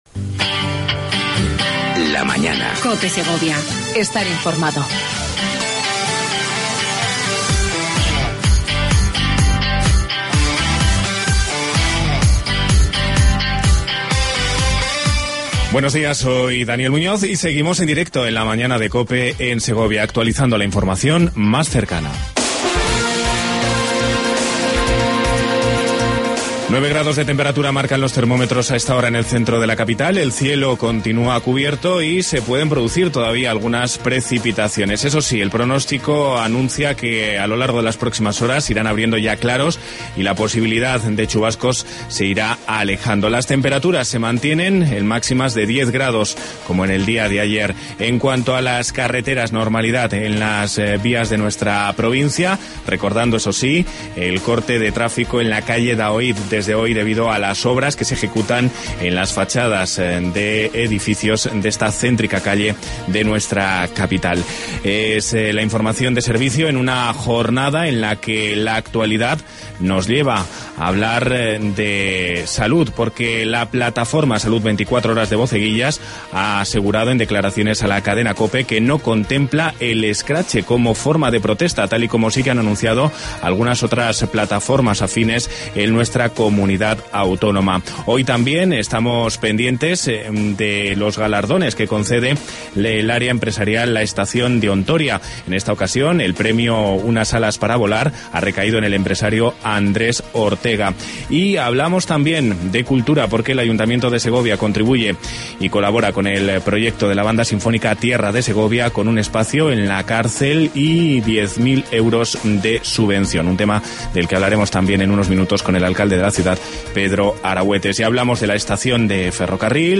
AUDIO: Entrevista con Pedro Arahuetes, Alcalde de Segovia.